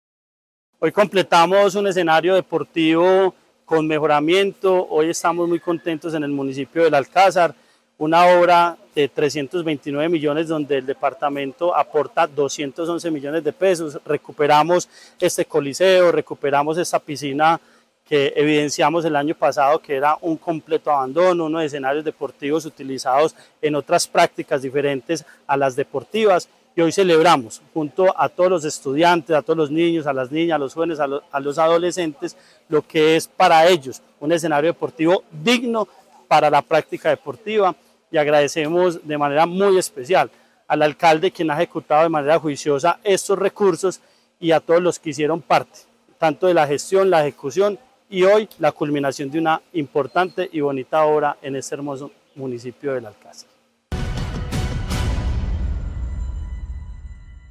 Andrés Duque Osorio, secretario de Deporte, Recreación y Actividad Física de Caldas